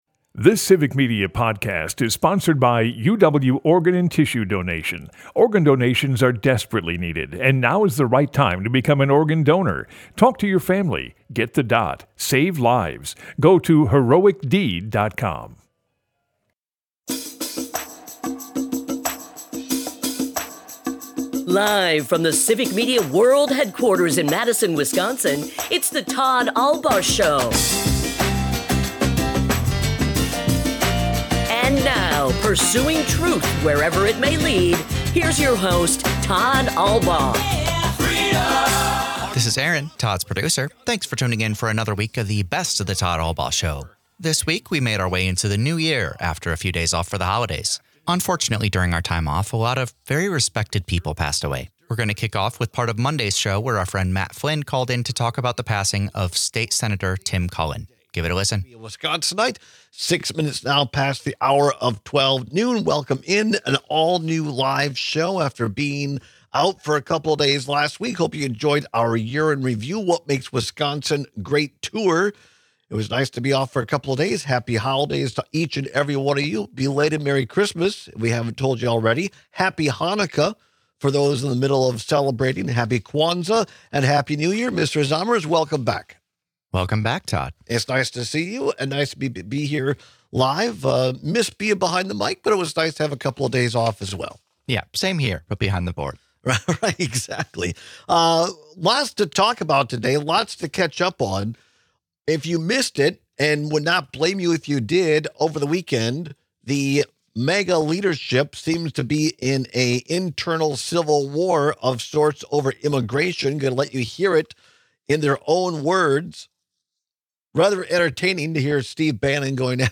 Broadcasts live 12 - 2p across Wisconsin.
Today, we celebrate the lives and legacy of former President Jimmy Carter and State Democratic Majority Leader Tim Cullen, among others. In remembering Tim Cullen, we bring some audio from our final interview with him from September.